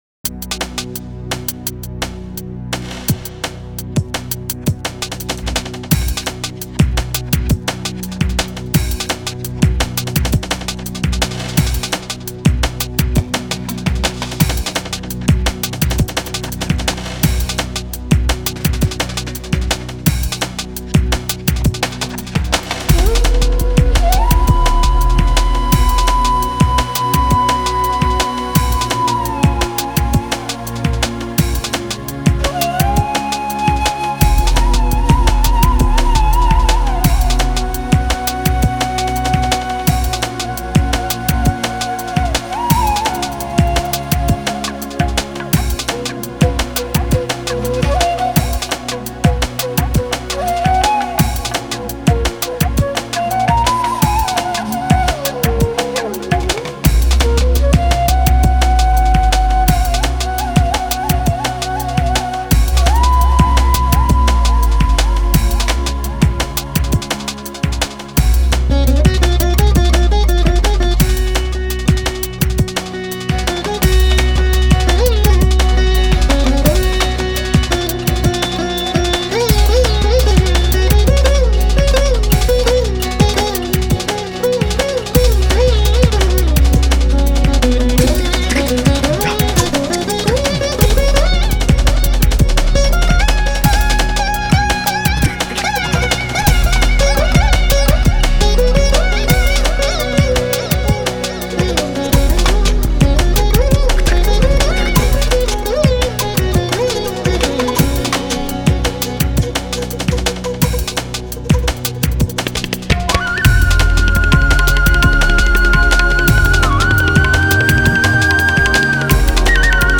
Жанр: Electronica, indian classical, Tribal, Drum n Bass